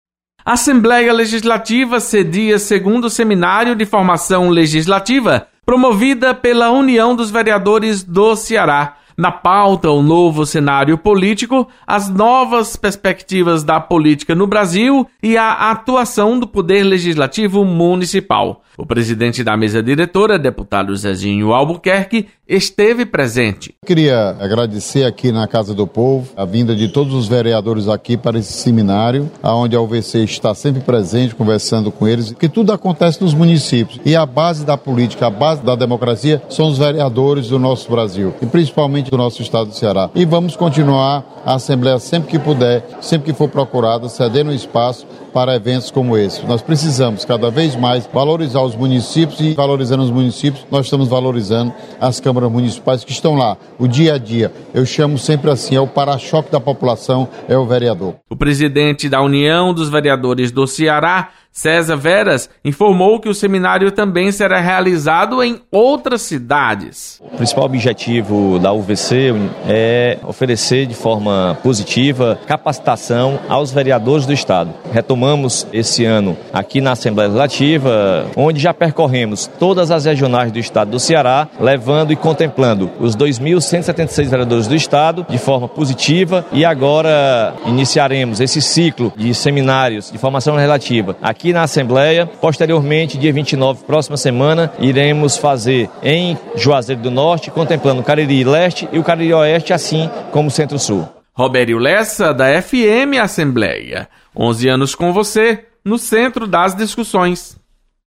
Presidente Zezinho Albuquerque prestigia seminário da União dos Vereadores do Ceará. Repórter